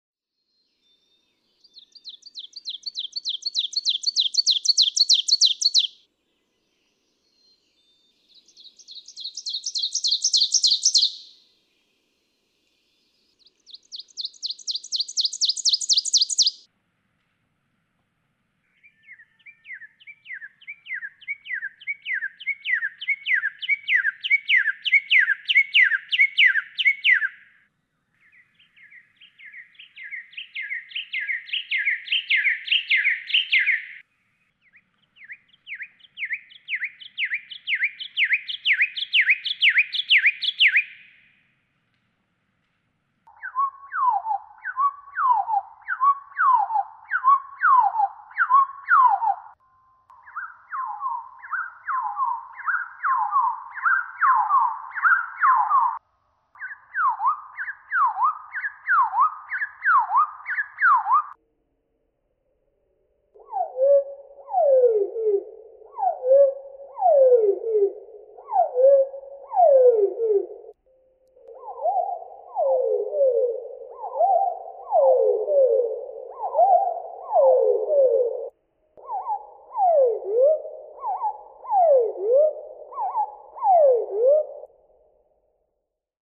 Ovenbird
♫558. Closer listening: Listen to one song from each of the above birds, first at normal speed, then half speed, then to a few excerpted teacher phrases from each bird at one-quarter and one-eighth speed. Now we begin to hear what the birds hear! (1:26)
558_Ovenbird.mp3